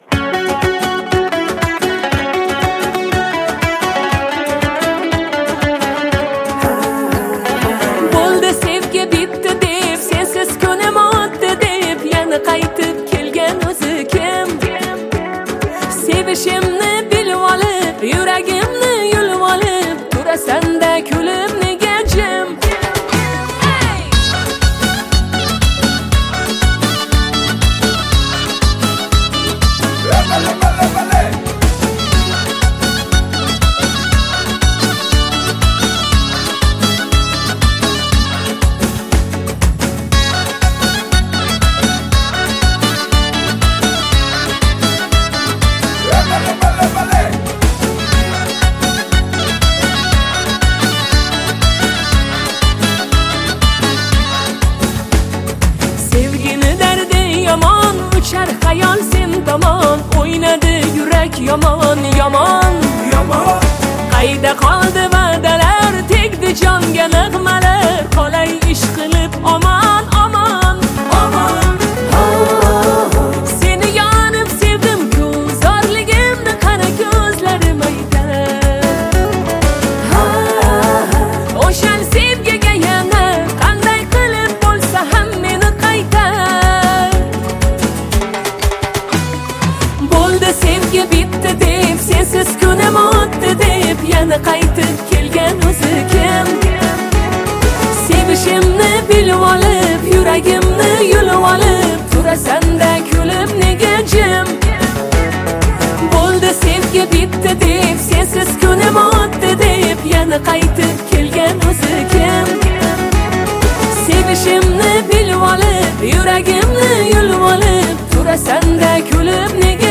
• Жанр: Арабские песни